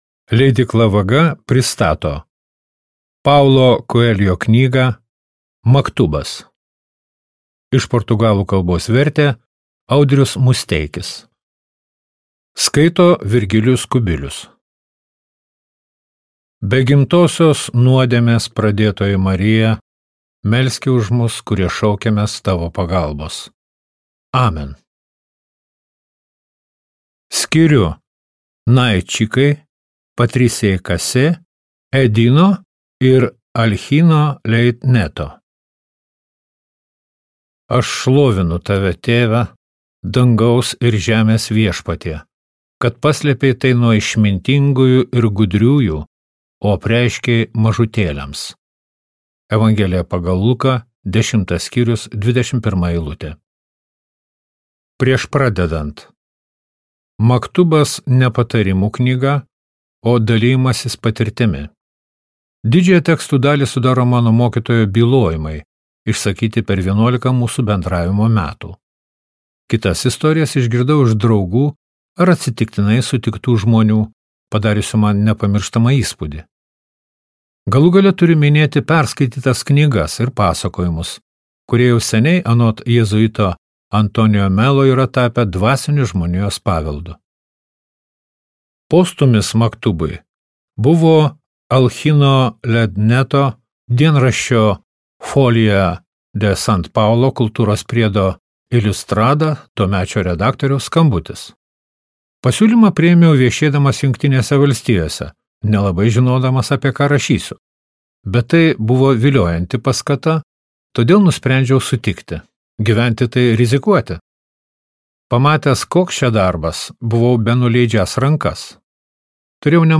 Maktubas | Audioknygos | baltos lankos